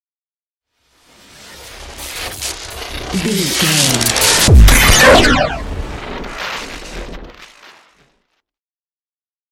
Whoosh to hit electricity large
Sound Effects
dark
futuristic
intense
tension